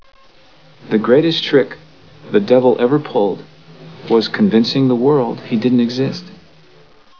. . . FROM THE MOVIE "The Usual Suspects" . . .